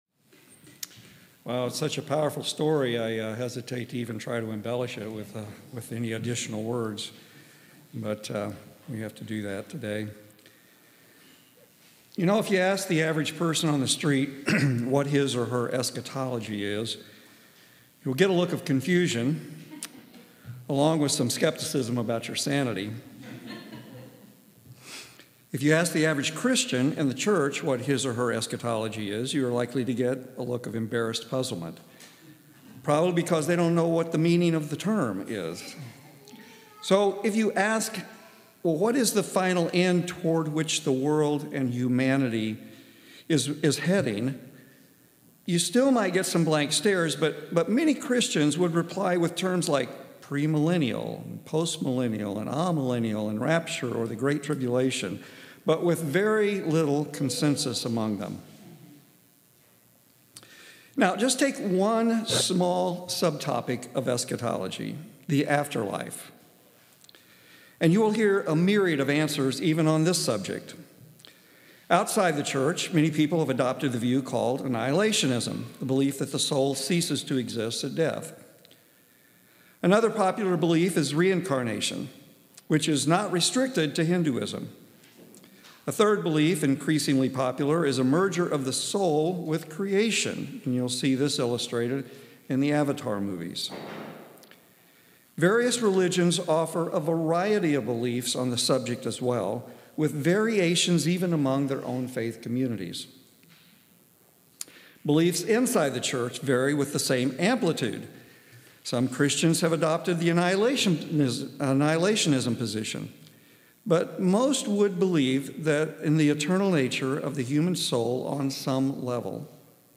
Sermon -